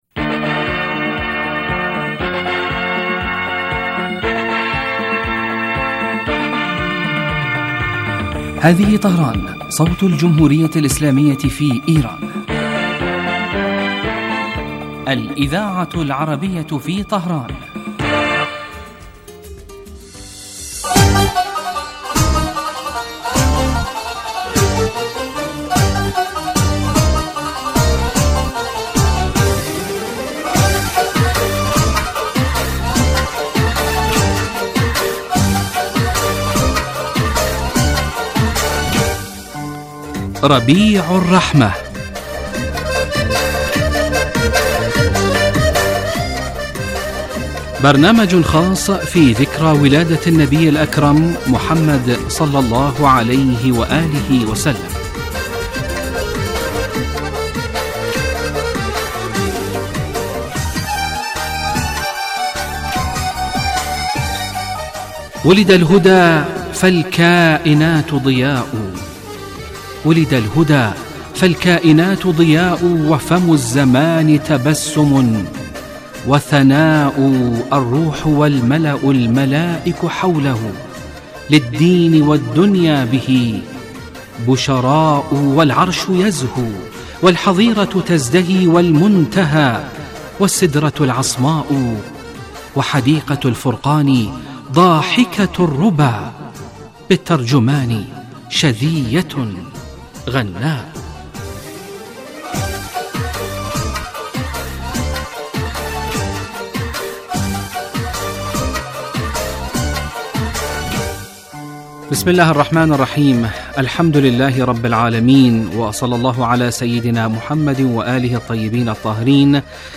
ربيع الرحمة Arabic Radio 11 views 30 September 2023 Embed likes Download إذاعة طهران-مولد النبي الأعظم